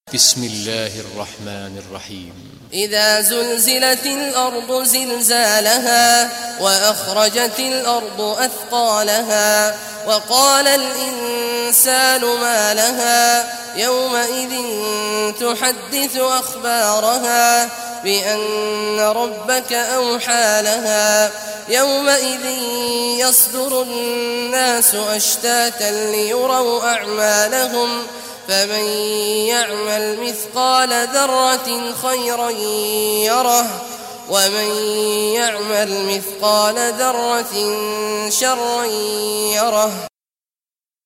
Surah Az-Zalzalah Recitation by Sheikh Awad Juhany
Surah Az-Zalzalah, listen or play online mp3 tilawat / recitation in Arabic in the beautiful voice of Sheikh Abdullah Awad al Juhany.